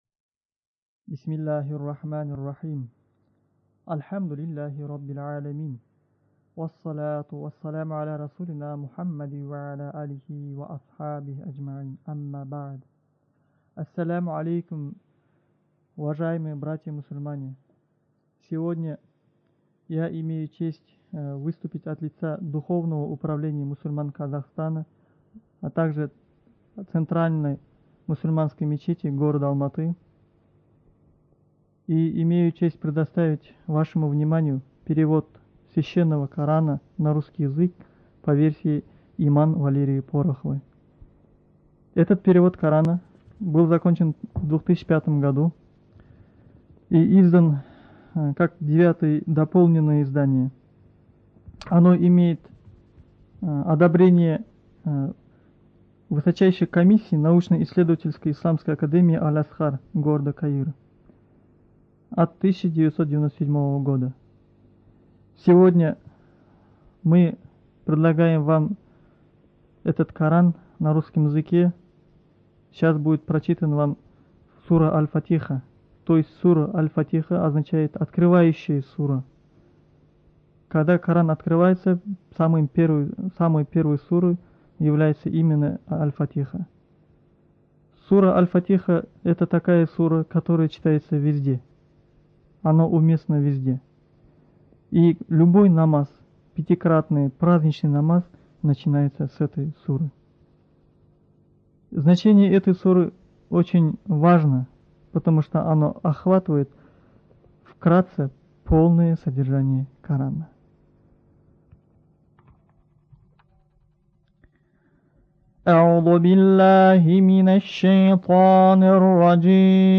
Студия звукозаписиКазахская республиканская библиотека для незрячих и слабовидящих граждан